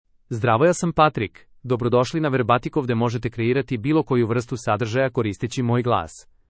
Patrick — Male Serbian AI voice
Patrick is a male AI voice for Serbian (Serbia).
Voice sample
Male
Patrick delivers clear pronunciation with authentic Serbia Serbian intonation, making your content sound professionally produced.